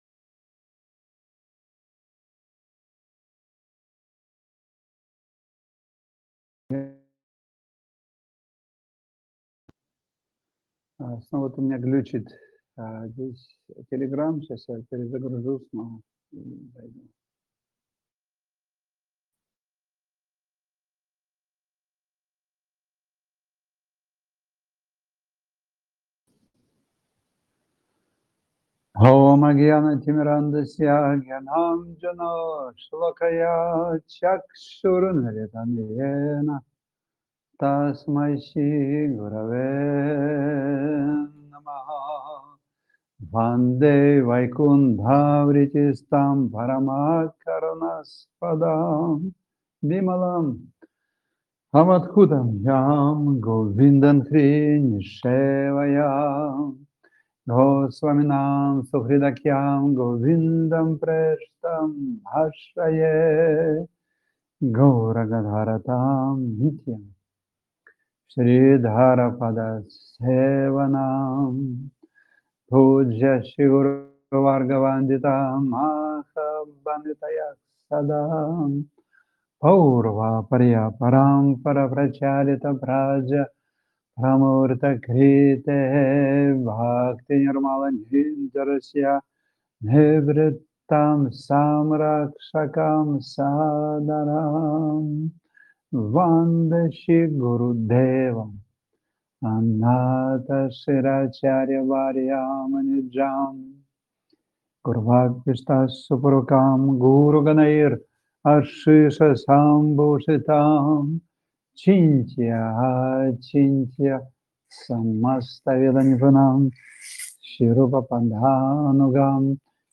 Пури Дхама, Индия